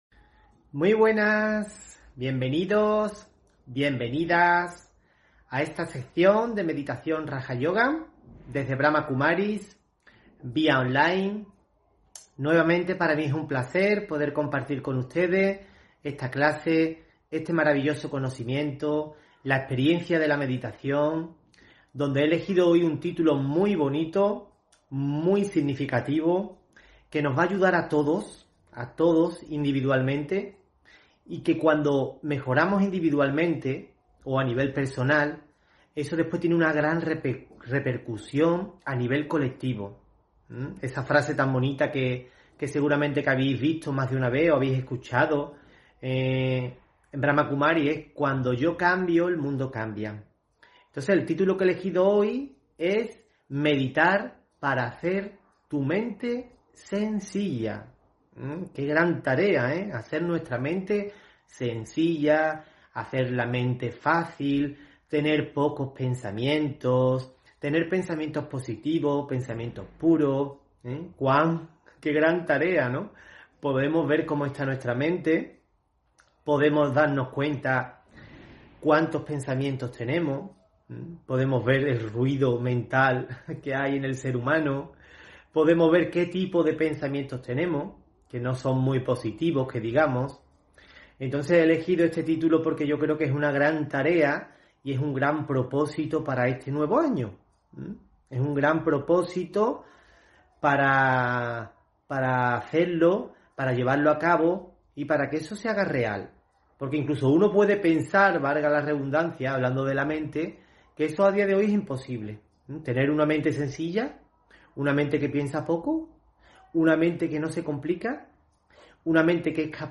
Audio conferencias